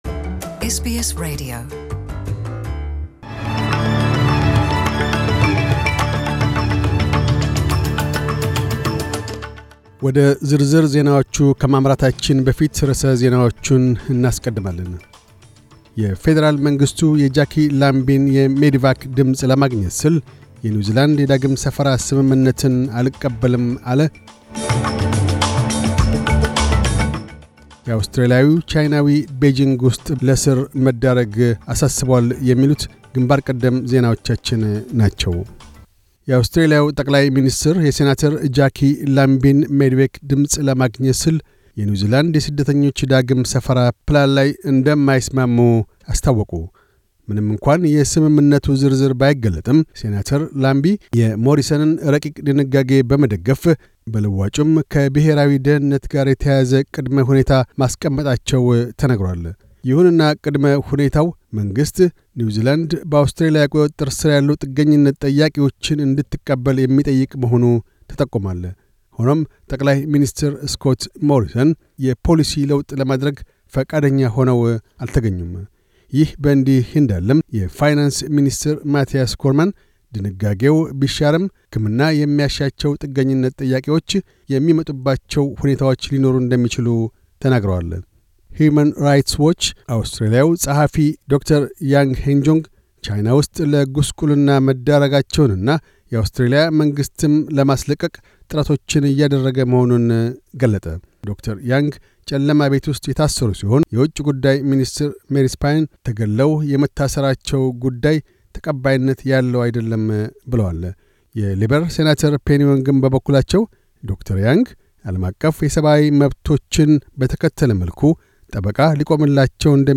News Bulletin 0212